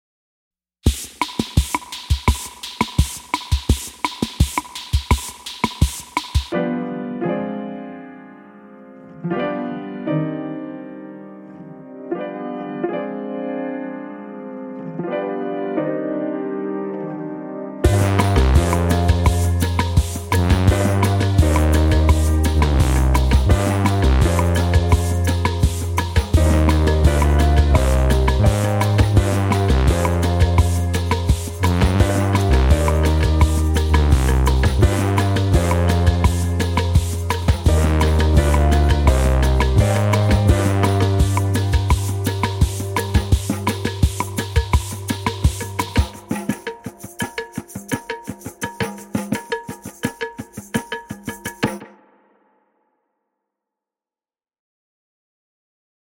Chroma Console’s Fuzz can be dialed way back by making use of the Sensitivity control, which decreases saturation.
Here, we’ve tilted the frequency spectrum towards the high end, which thins out this upright piano in a tasteful way. Add a little Cassette, Vibrato, and Reels, and it sounds like you’re recording straight to an early 70’s tape deck that needs service. Add a vintage drum machine, synth bass via the Moog Matriarch, and some auxiliary percussion and you’re making music.